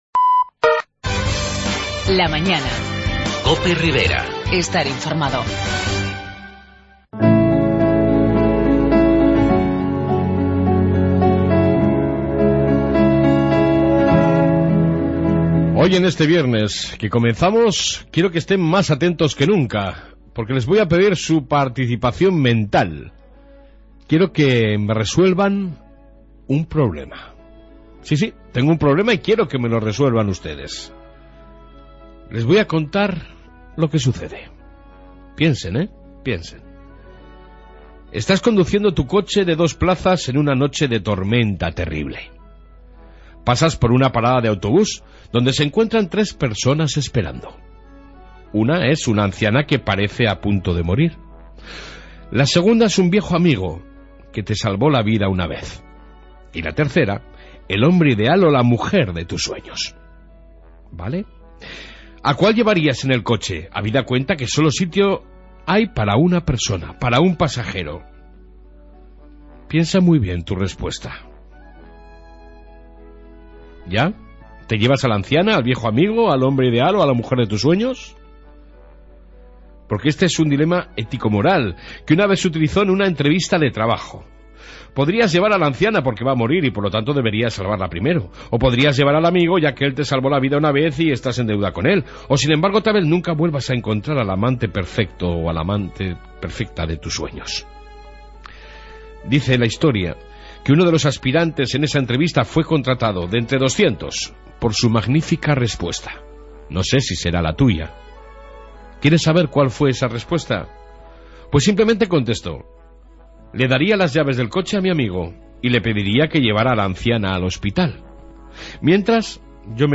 AUDIO: En esta 1 parte reflexión diaria, Informe Policía Municipal, Noticias riberas y Entrevista Cortes en fiestas con su alcaldesa Conchi Ausejo